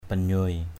/ba-ɲʊoɪ/ (cv.) binyuai b{=v& (t.) ủ dột, buồn rượi. aia mbaok banyuai a`% _O<K b=v& vẻ mặt ủ dột.